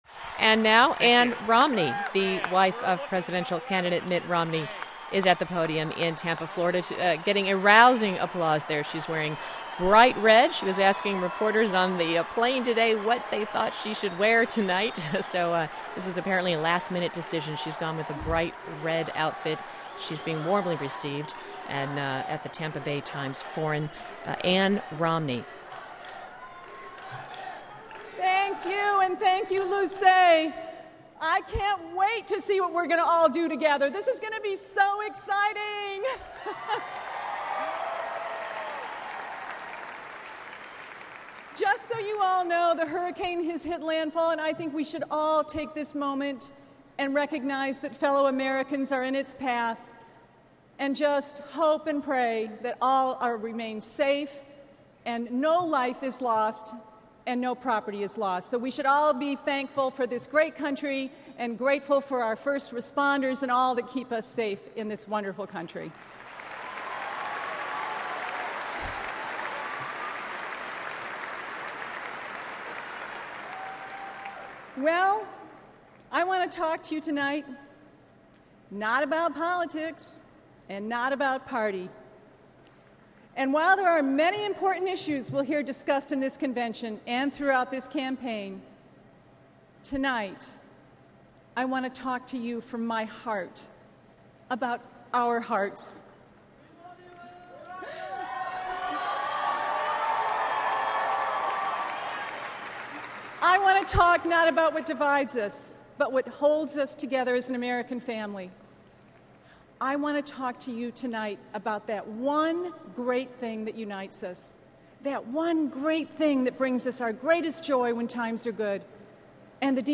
Transcript: Ann Romney's Convention Speech
Full transcript of Ann Romney's speech as prepared for delivery at the Republican National Convention.